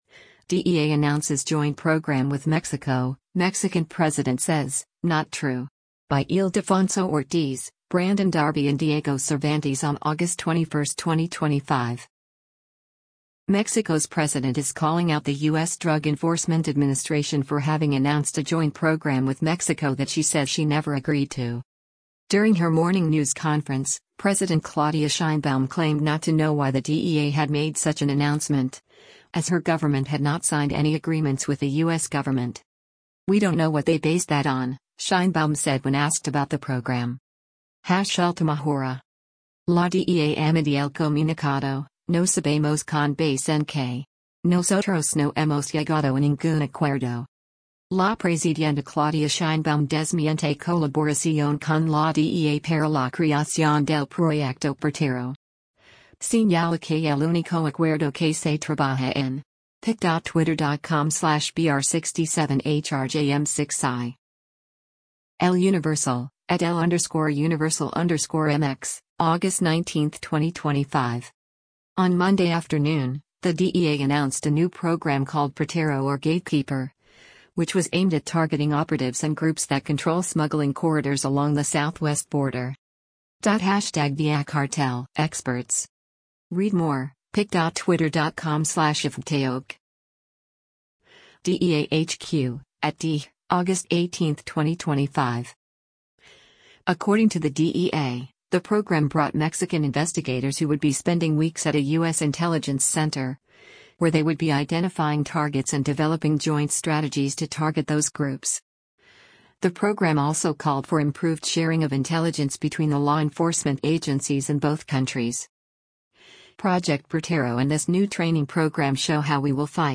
Mexican President Claudia Sheinbaum takes questions during a news conference.